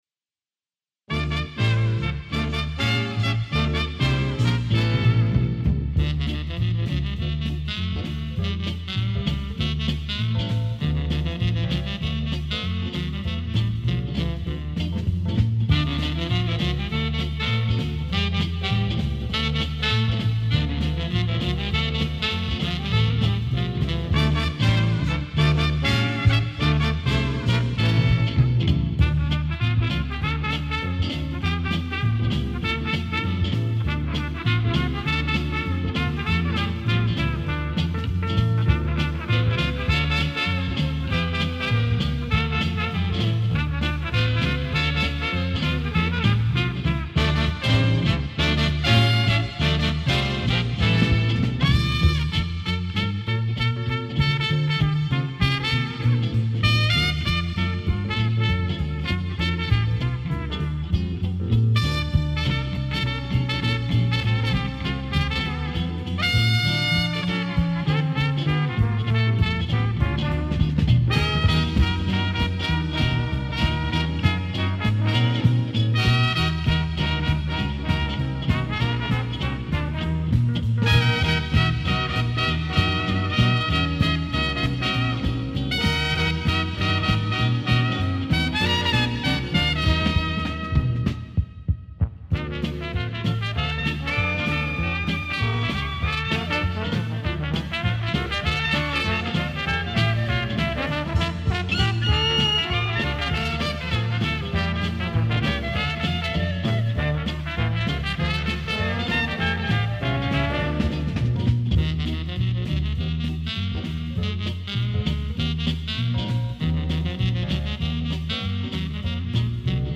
А до святок с калядой - промежуток небольшой! Инструментальный пенсионерский зачин 2023 ...